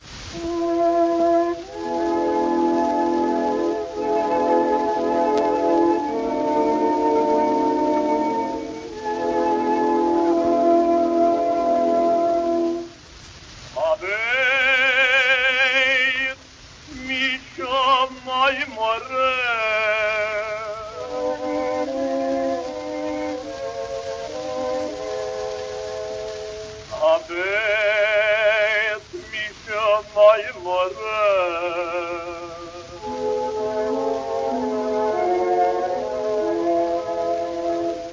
16. ניסיתי להקליט בעזרת Audacity בהקלטה פנימית, יצא מעולה